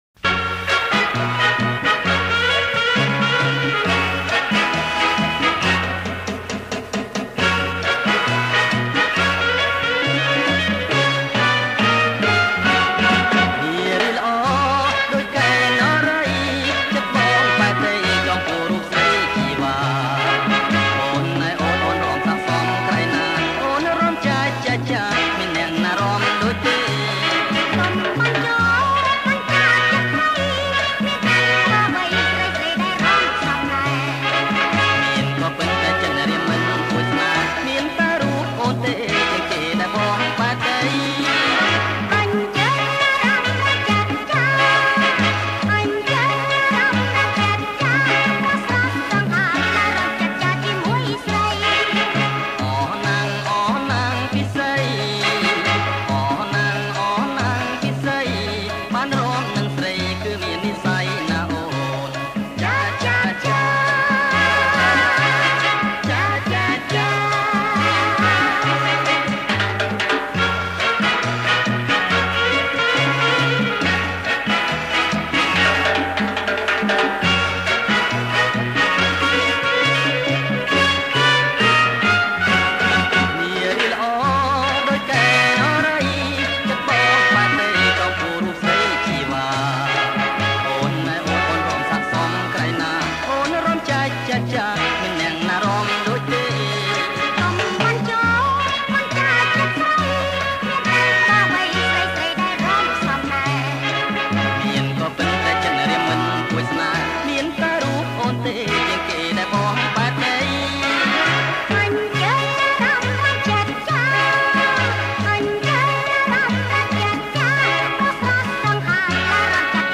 • ប្រគំជាចង្វាក់ Cha Cha Cha